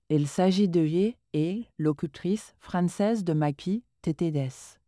Supported voice names and samples generated with these models are also mentioned in the following table.
🔉FR-FR.Female.Female-1
FR-FR.Female.Female-1_MagpieTTS.wav